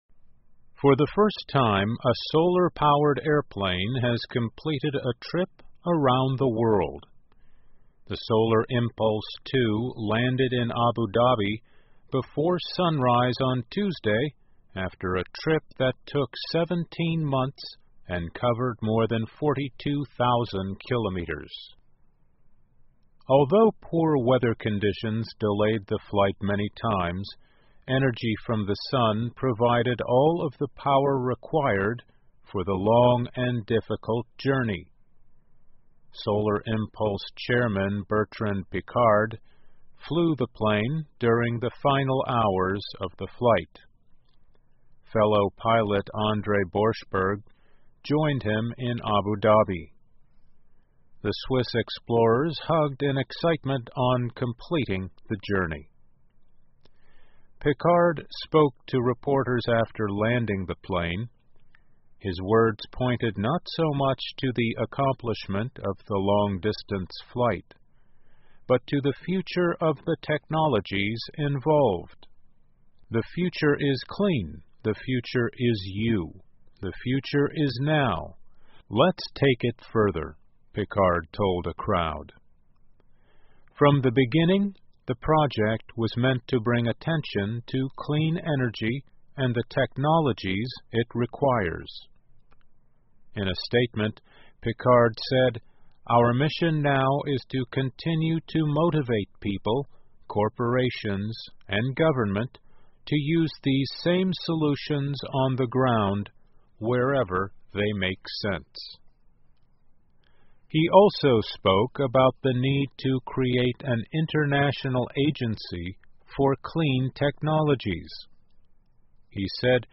在线英语听力室最大太阳能飞机完成环球之旅的听力文件下载,2016年慢速英语(七)月-在线英语听力室